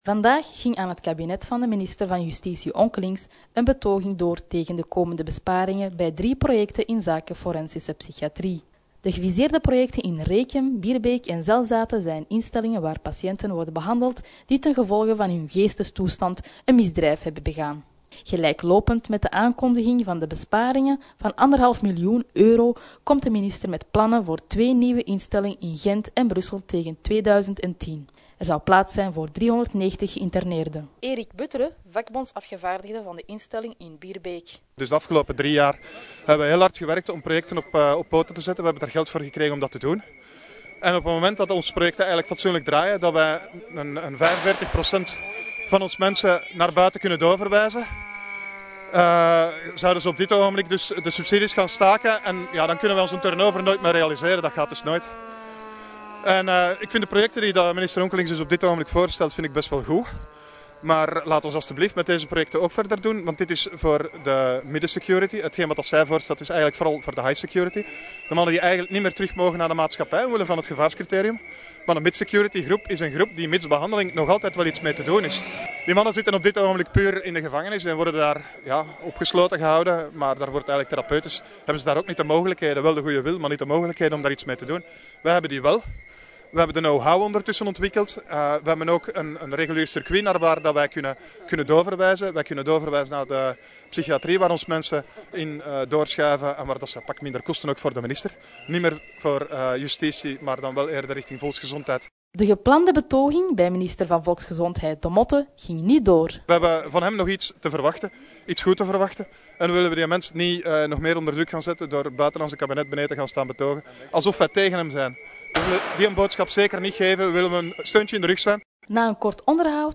[Radioverslag] Bonden verzetten zich tegen besparingen forensische psychiatrie
BRUSSEL -- Voor het kabinet van Minister van Justitie Onkelinx (PS) was er maandagmorgen een betoging tegen de komende besparing bij drie projecten inzake forensische psychiatrie. De geviseerde projecten in Rekem, Bierbeek en Zelzate zijn instellingen waar patiënten worden behandeld die ten gevolge van hun geestestoestand een misdrijf hebben begaan.
manifestatie onkelinkx.mp3